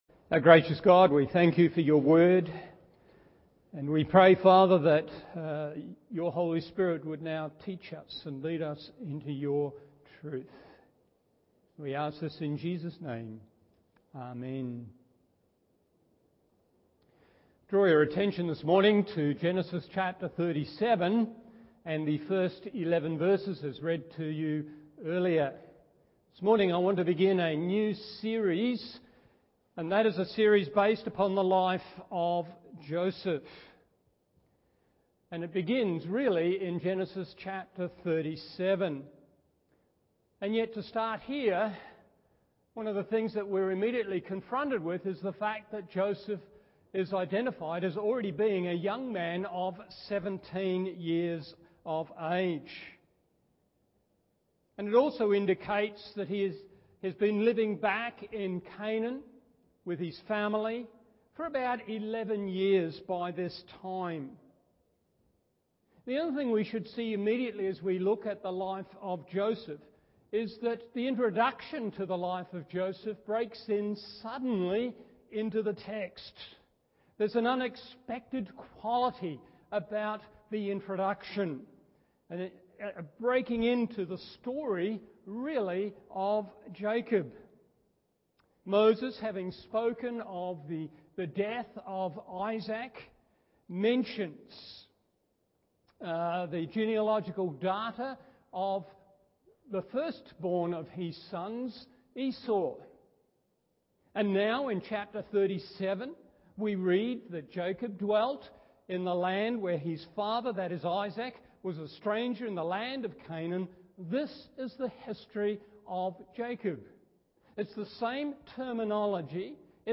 Morning Service Genesis 37:1-11 1.